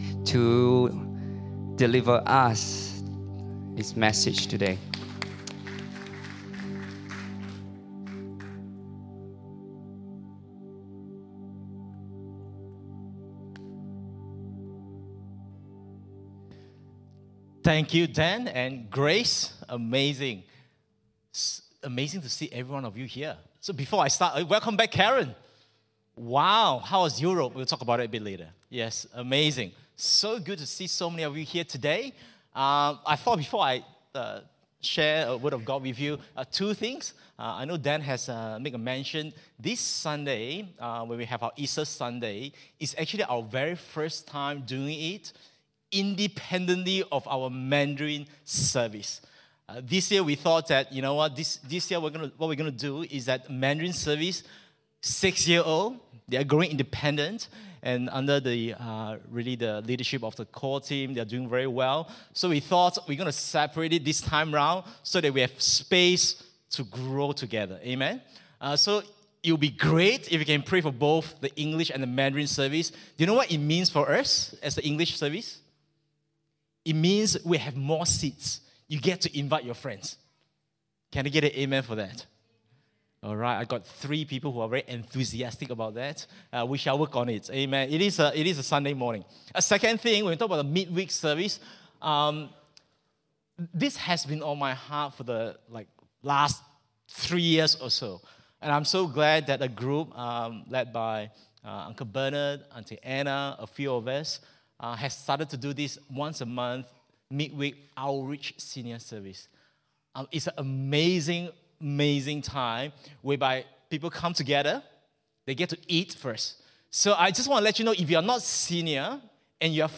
English Sermons | Casey Life International Church (CLIC)